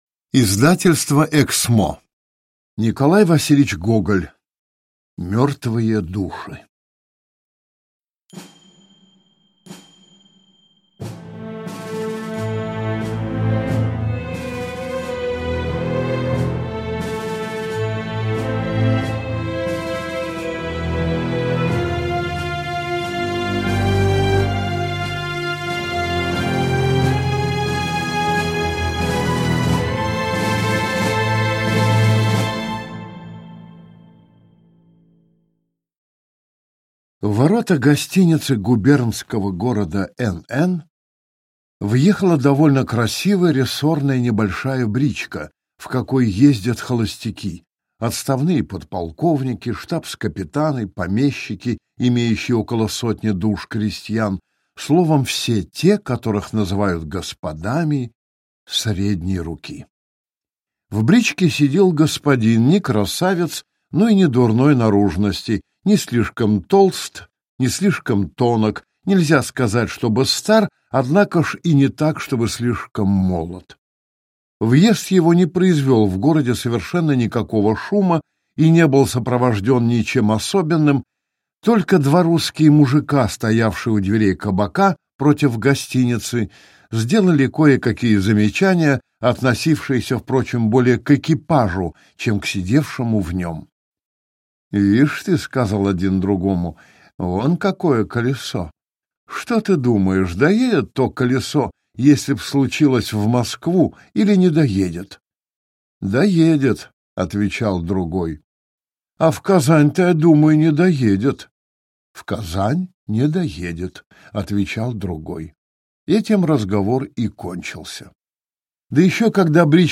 Аудиокнига Мертвые души | Библиотека аудиокниг